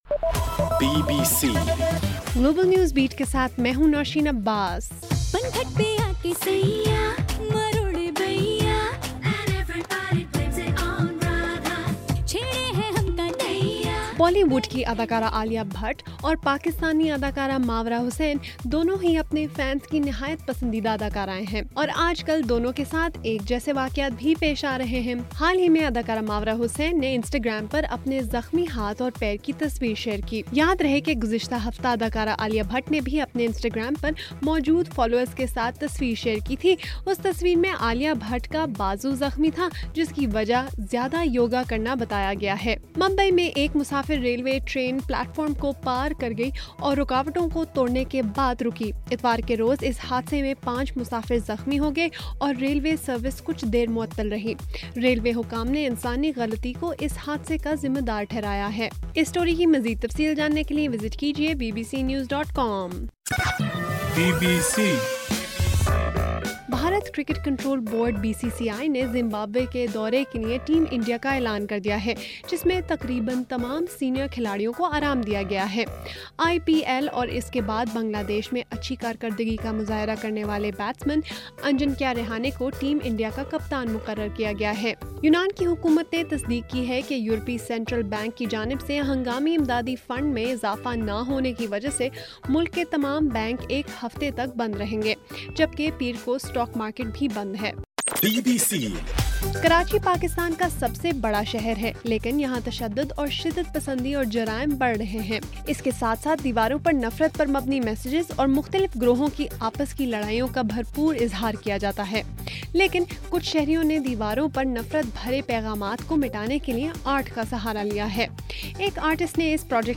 جون 29: رات 12 بجے کا گلوبل نیوز بیٹ بُلیٹن